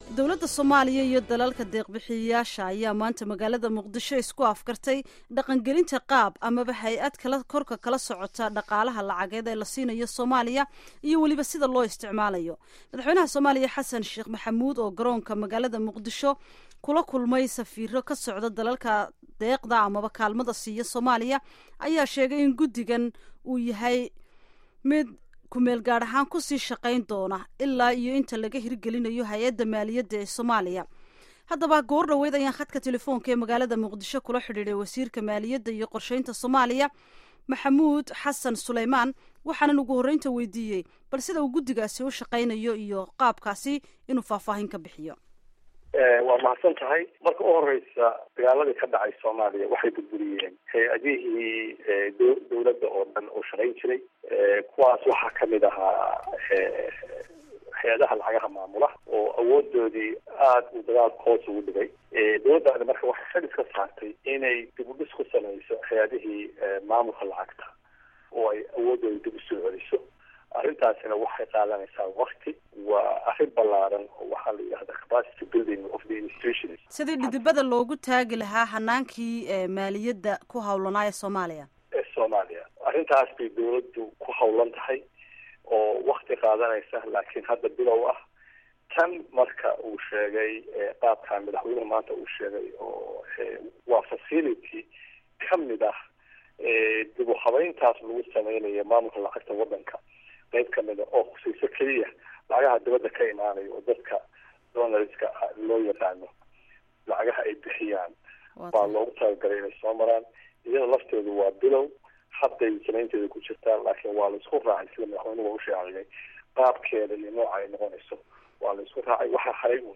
Dhageyso wareysiga wasiirka maaliyadda